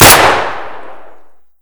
shoot_1.ogg